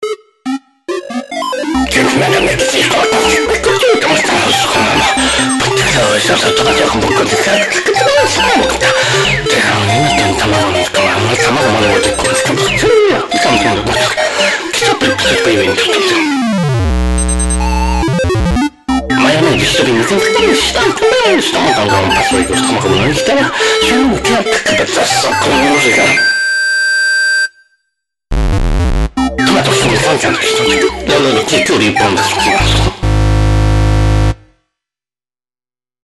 May be, probably trying a fast rap?